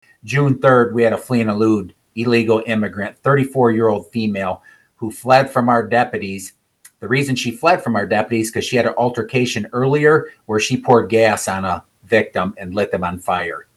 As part of the press conference, other sheriffs from across the state told similar stories of how illegal immigration has impacted their communities.